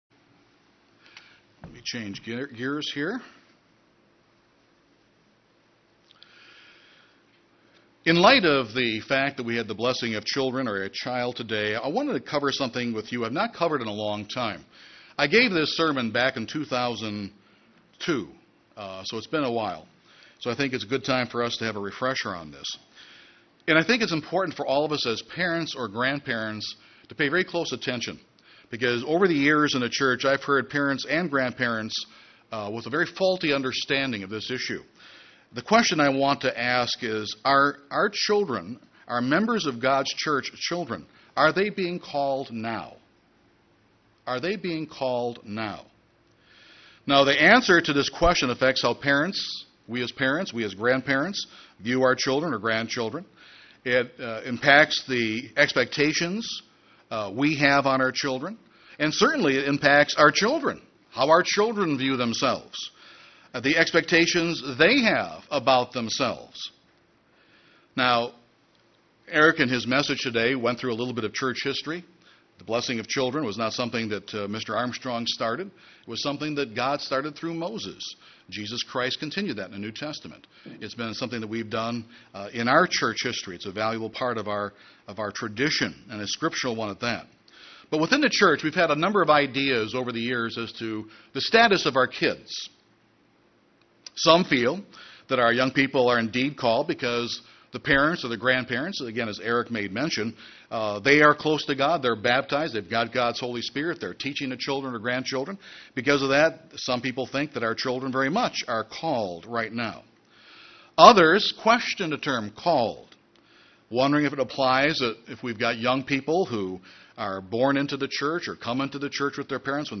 This sermon provides that answer!